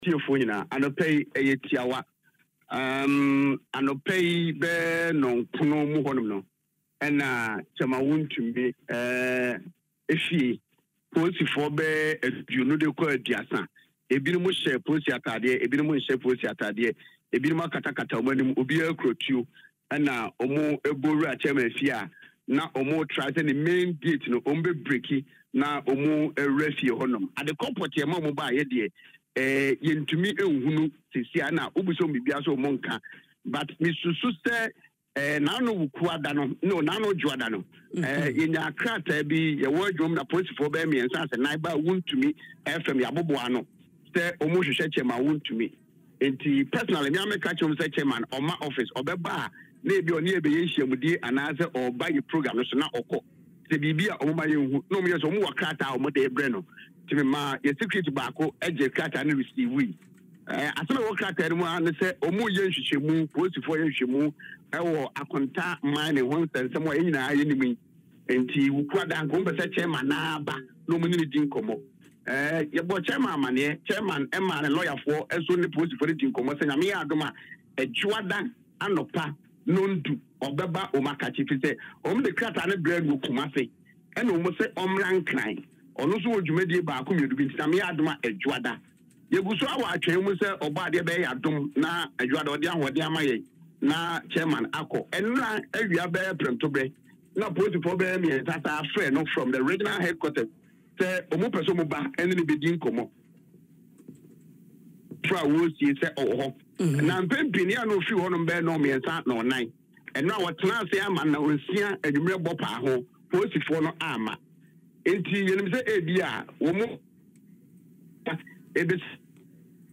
confirmed the development in an interview on Adom FM’s Dwaso Nsem.